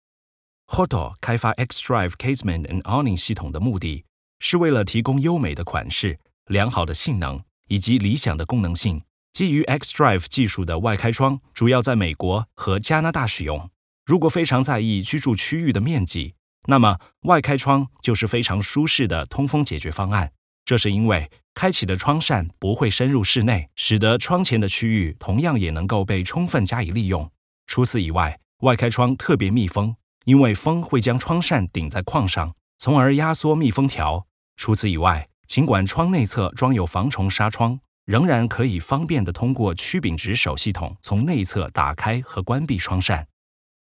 Commercial, Young, Natural, Friendly, Warm
Audio guide